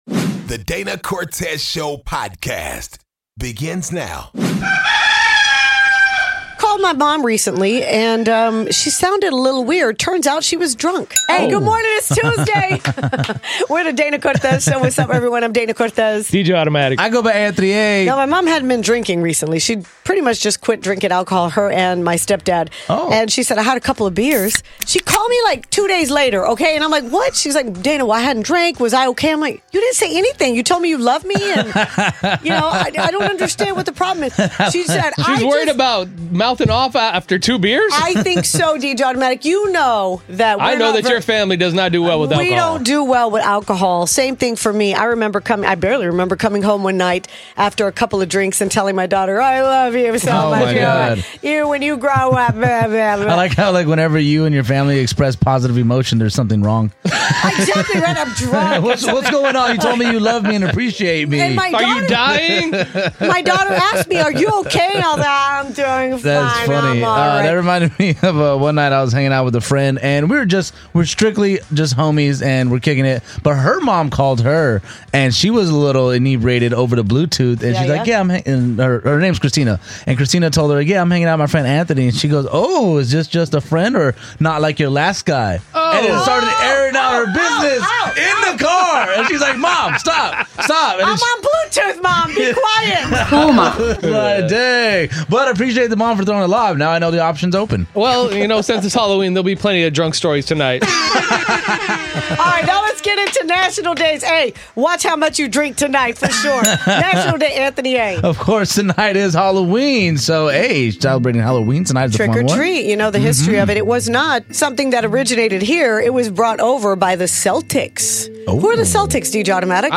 Is there such thing as too sexy of a costume? A male listener calls in and lets us know he would never let his girl dress like Ice Spice.